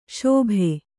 ♪ śobhe